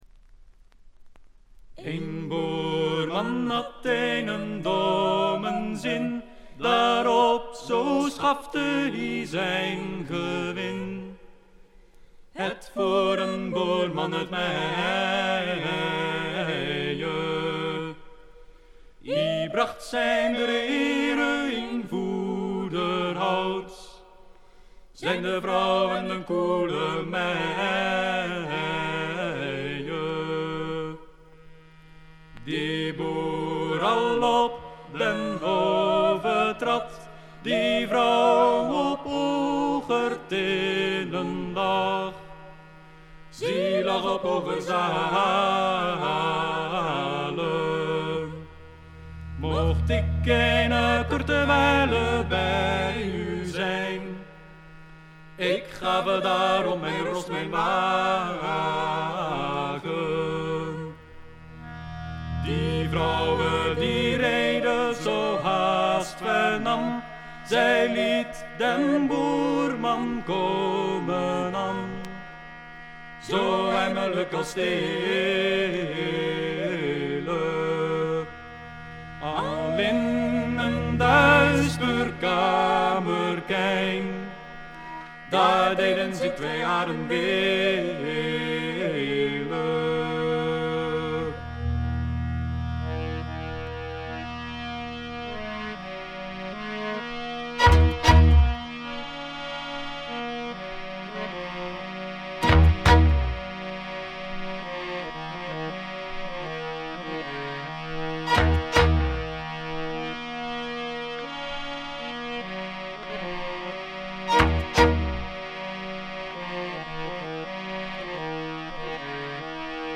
女性ヴォーカルを擁した5人組。
試聴曲は現品からの取り込み音源です。
Recorded At - Farmsound Studio